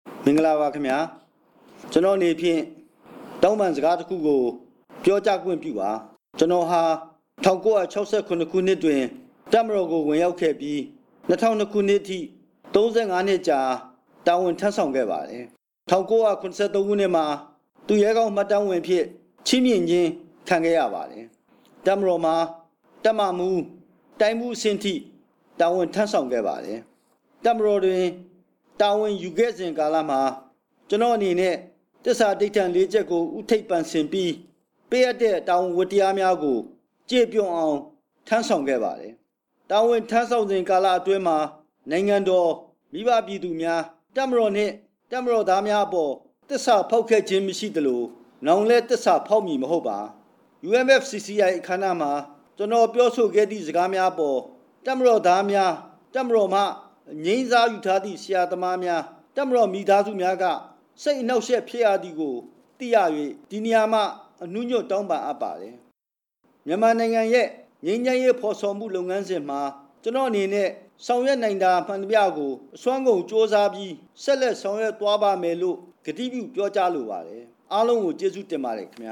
တပ်မတော်မိသားစုကို တောင်းပန်ကြောင်း ဝန်ကြီး ဦးအောင်မင်းက မီဒီယာတွေကို မနေ့က ထုတ်ပြန်ပေးပို့လိုက်တဲ့ အသံဖိုင်အပြည့်အစုံကို နားဆင်နိုင်ပါတယ်။